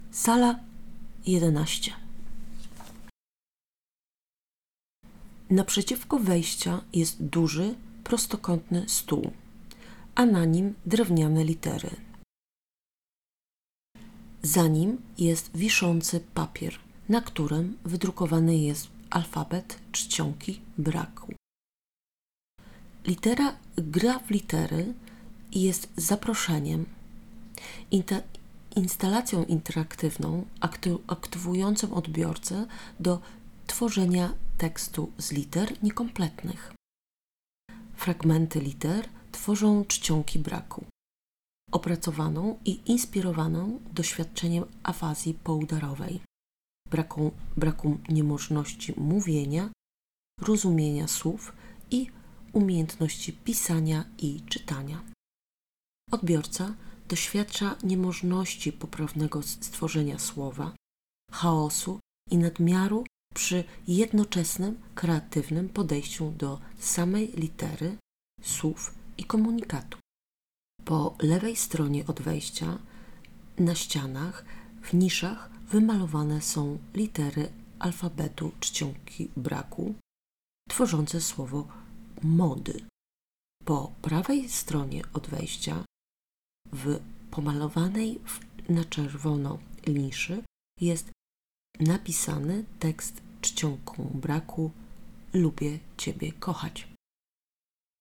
audio-description